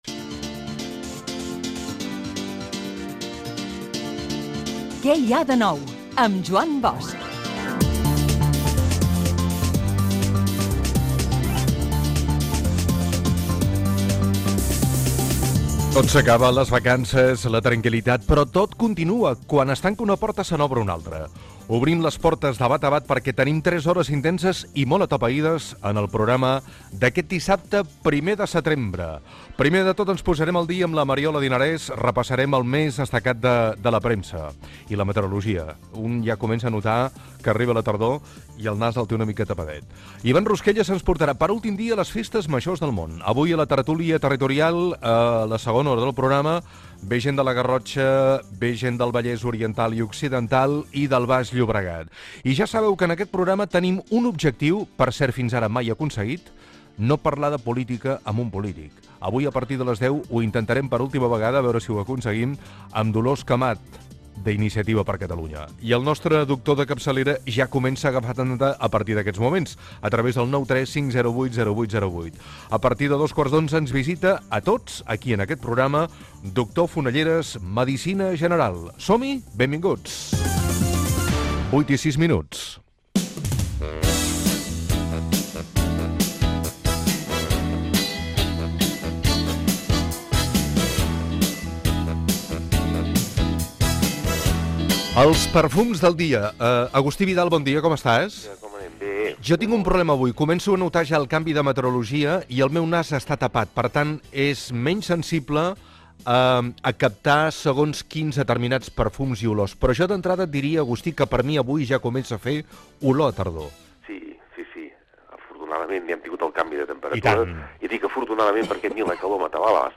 Careta, inici i sumari del programa.
Entreteniment
FM